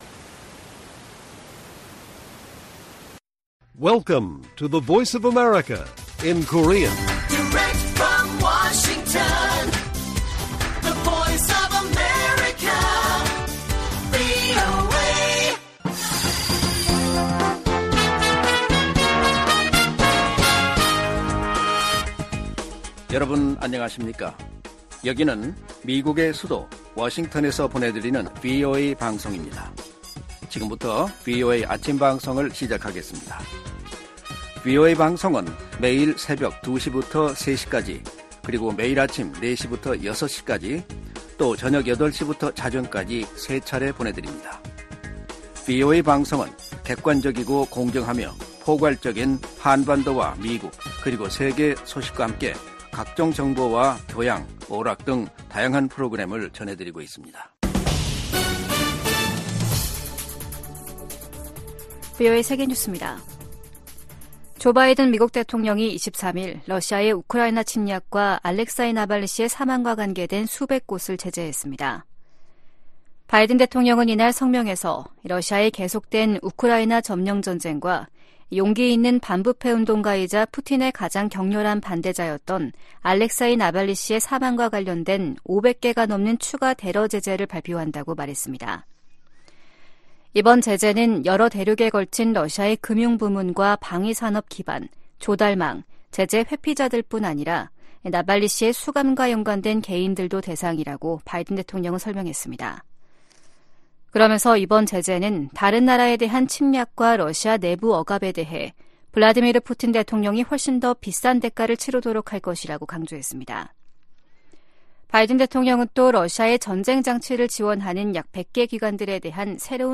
세계 뉴스와 함께 미국의 모든 것을 소개하는 '생방송 여기는 워싱턴입니다', 2024년 2월 24일 아침 방송입니다. '지구촌 오늘'에서는 브라질 리우데자네이루 주요20개국(G20) 외교장관회의에서 이스라엘의 가자지구 군사작전에 대한 비판이 쏟아지면서 미국이 고립되는 모습을 보인 소식 전해드리고, '아메리카 나우'에서는 무인 탐사선 '오디세우스'가 달 착륙에 성공한 이야기 살펴보겠습니다.